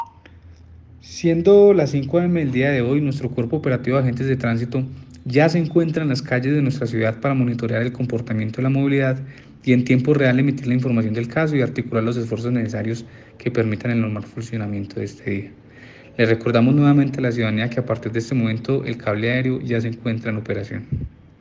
Secretario de Tránsito Manizales